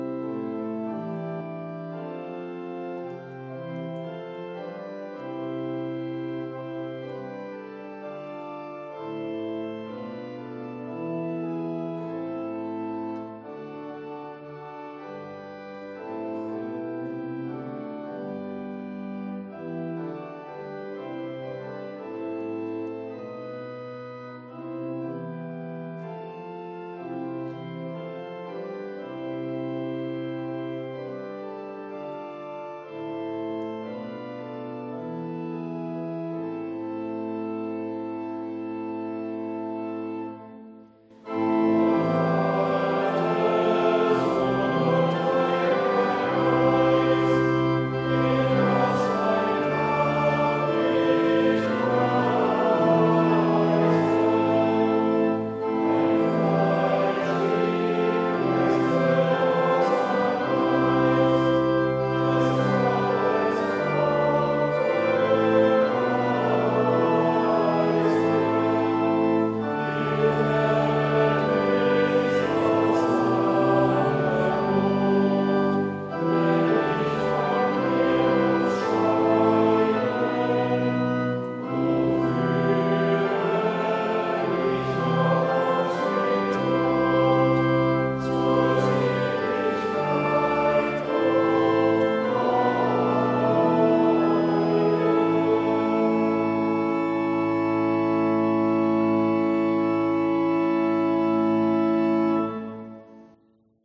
Gottesdienst am 07.08.2022
Audiomitschnitt unseres Gottesdienstes vom 8. Sonntag nach Trinitatis 2022.